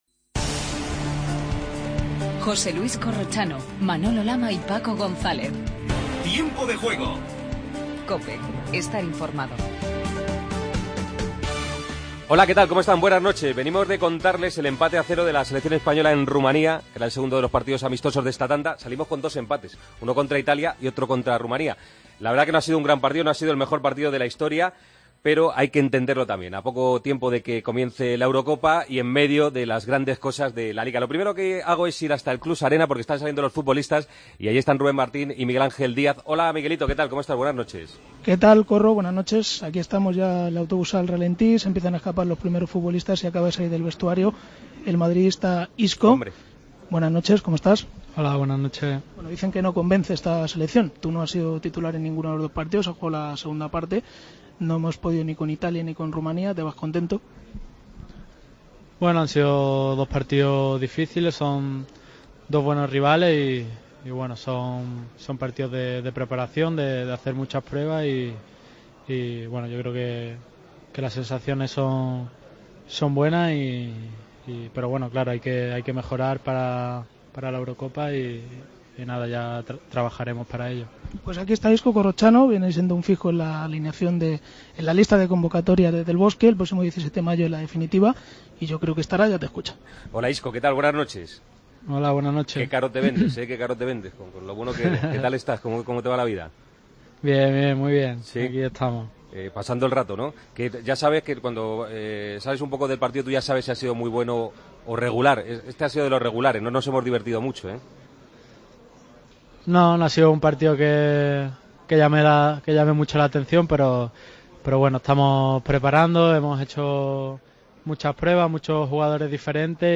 España empata en Rumanía y sigue dejando dudas. Hablamos con Isco y escuchamos a Del Bosque y a Sergi Roberto....